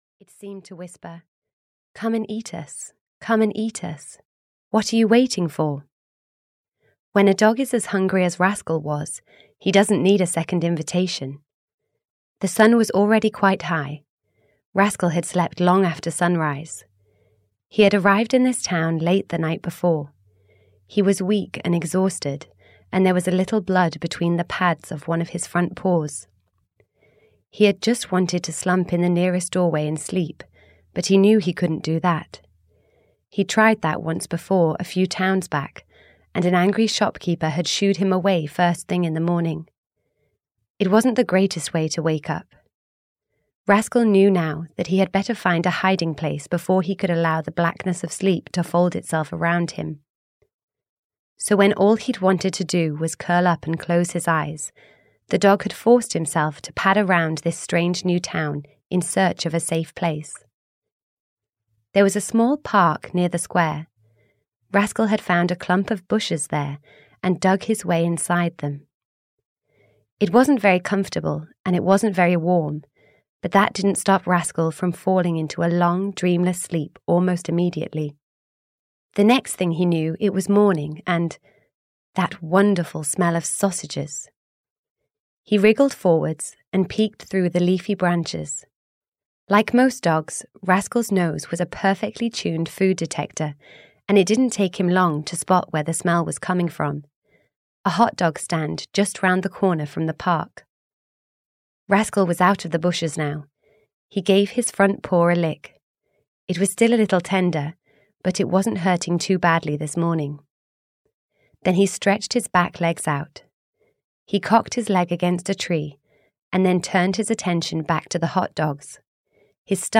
Rascal 3 - Running For His Life (EN) audiokniha
Ukázka z knihy